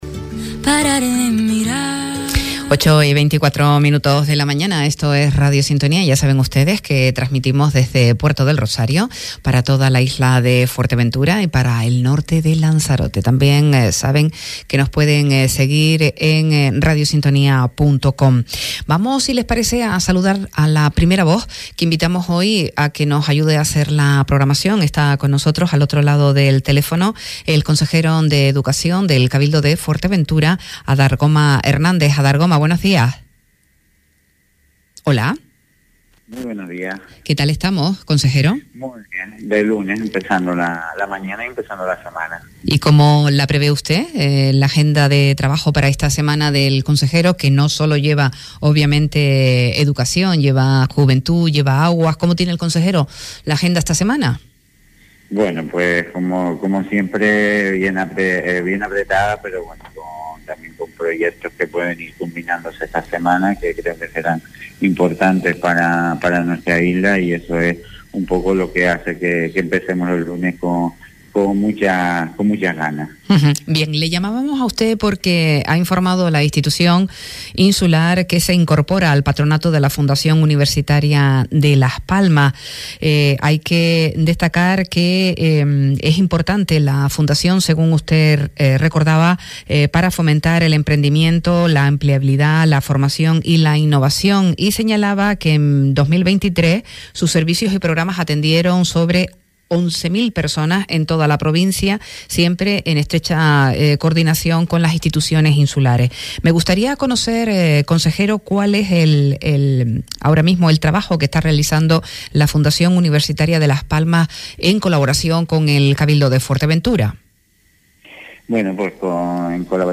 En relación a este asunto, hemos podido conversar esta mañana, en «A primera hora», con el consejero insular de Educación, Adargoma Hernández, quien ha sido nombrado representante de la corporación insular en el patronato.
Entrevistas